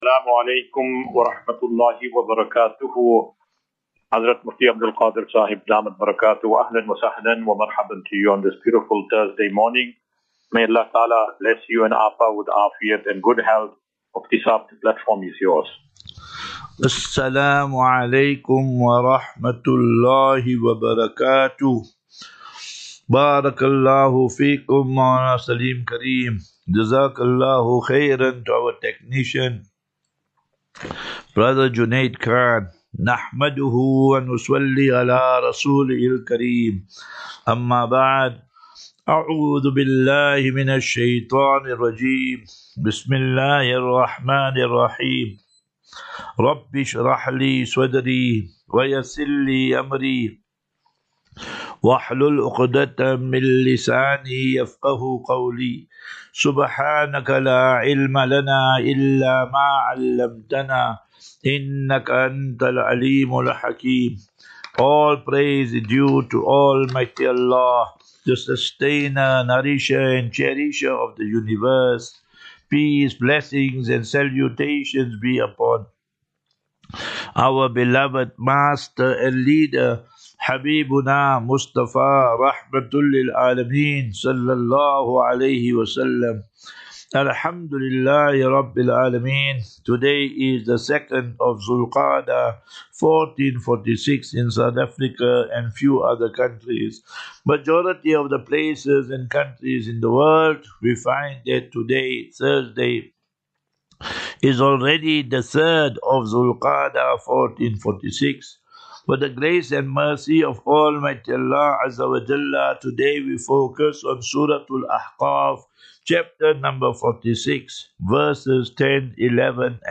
1 May 01 May 2025. Assafinatu - Illal - Jannah. QnA